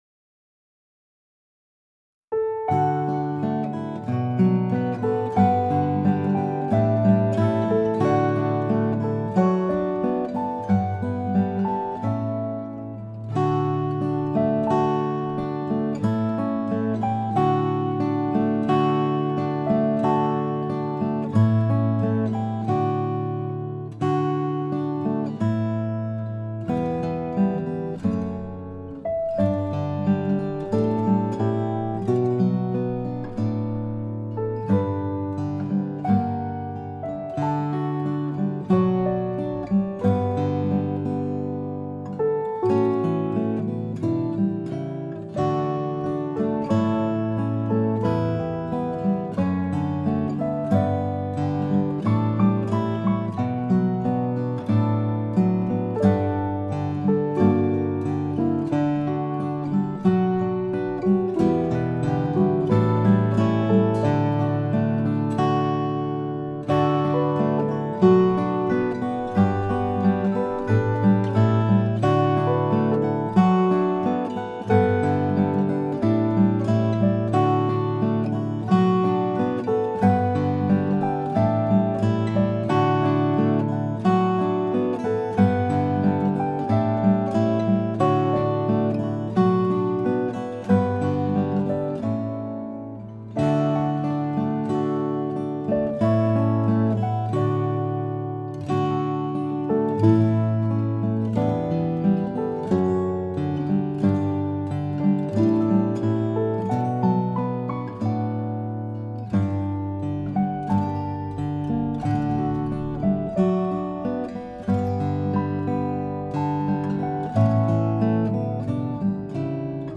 Guitar & Piano